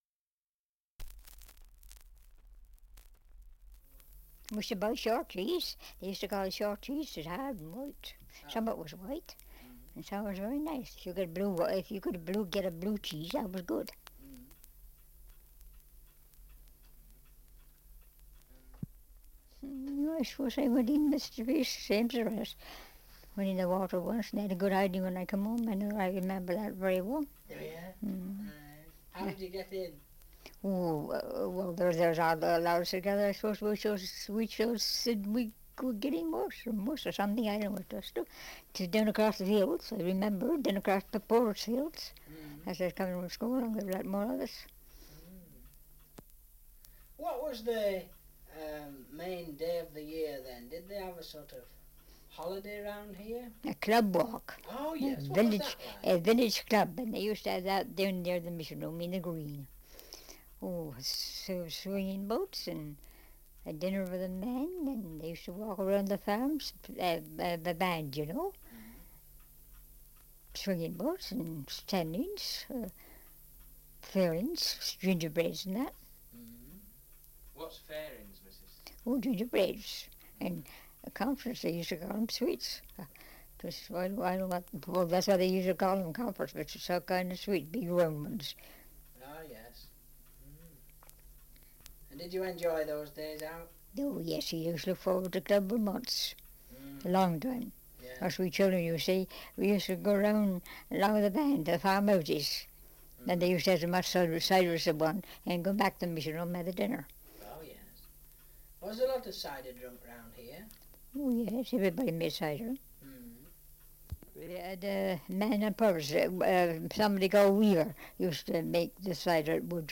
Survey of English Dialects recording in Pitminster, Somerset
78 r.p.m., cellulose nitrate on aluminium